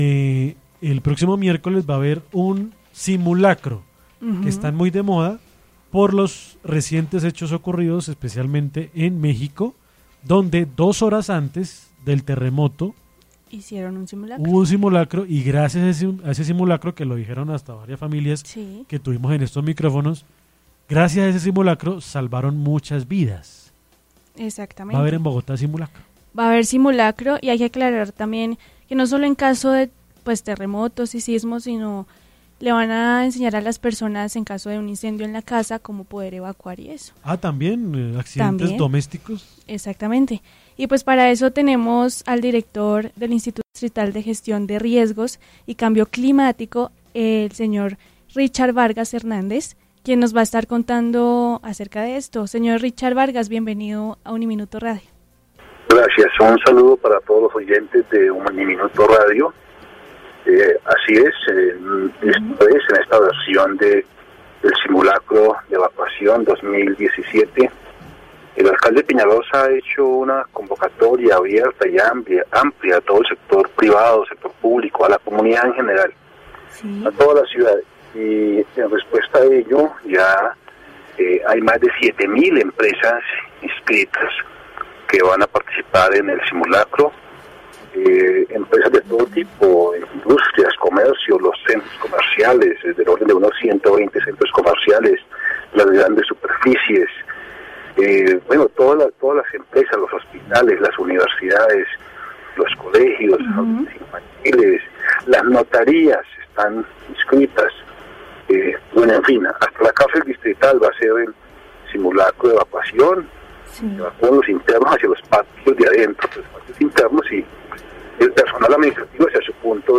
En los micrófonos de UNIMINUTO Radio estuvo el director del Instituto Distrital de Riesgos y Cambio Climático (IDIGER) Richard Vargas, quien dio detalles sobre el próximo Simulacro de evacuación que se llevará a cabo este miércoles 25 de octubre en toda Bogotá.